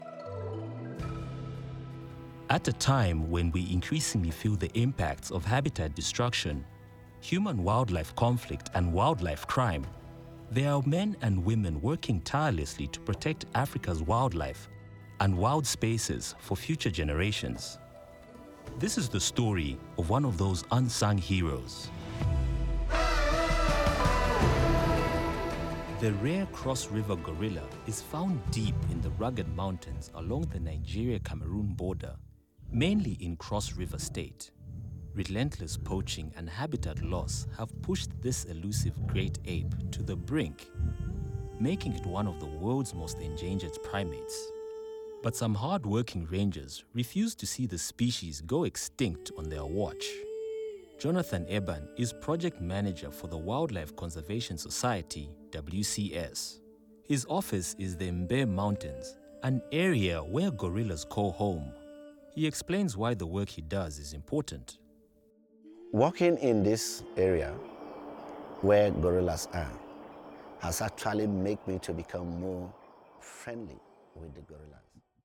Inglés (África)
Sennheiser MKH 416
ProfundoBajo